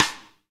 Index of /90_sSampleCDs/Roland L-CD701/KIT_Drum Kits 6/KIT_Fatso Kit